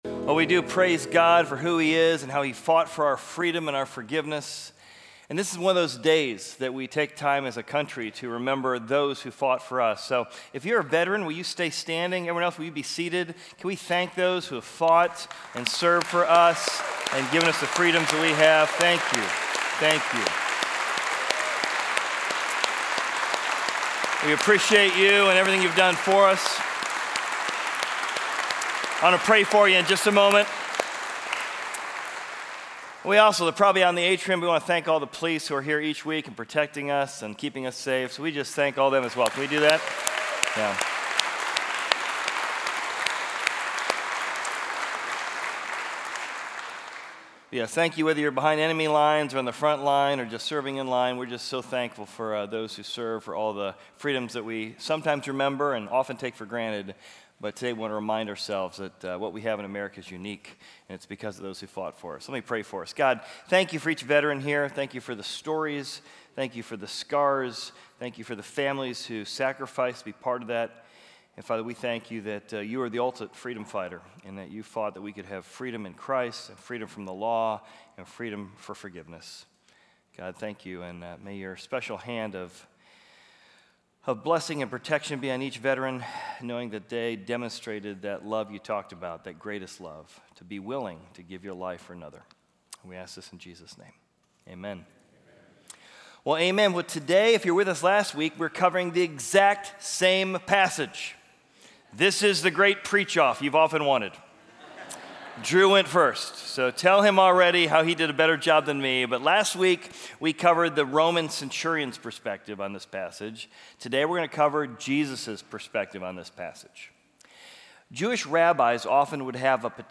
Equipping Service / Matthew: The Brightest Worship In The Darkest Hour / Worship Through Prophecy